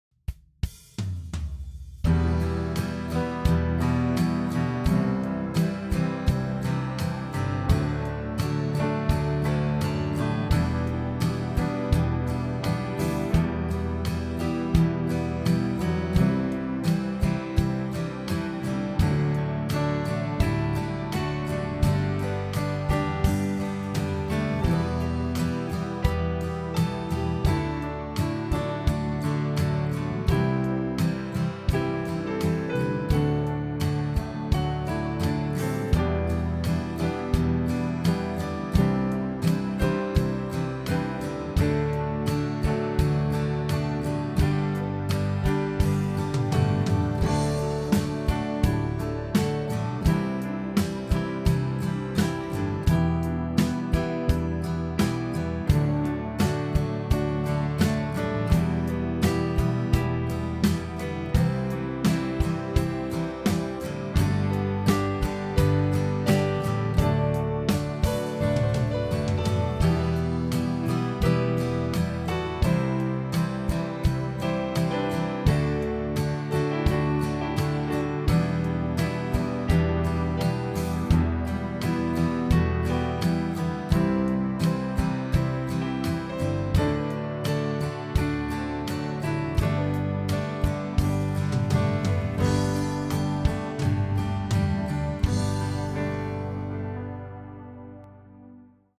Home > Music > Rock > Smooth > Medium > Laid Back